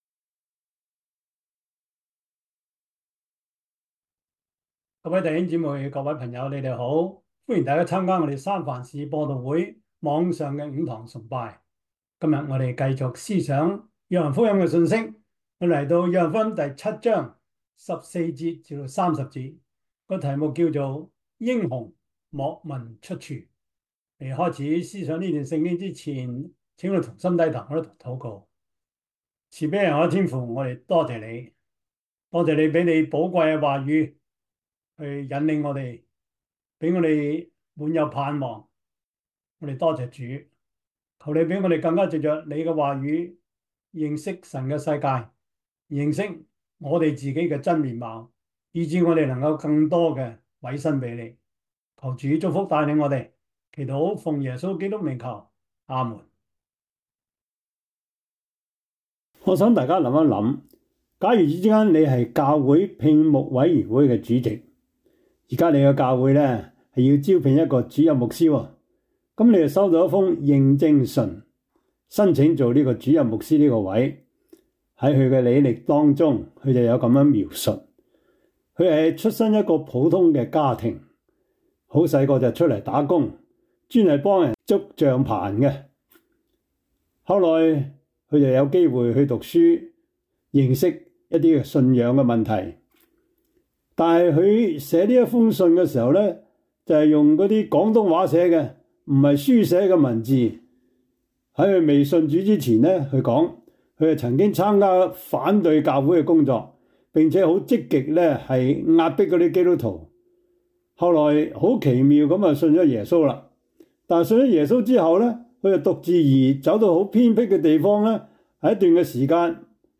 約翰福音 7:14-30 Service Type: 主日崇拜 約翰福音 7:14-30 Chinese Union Version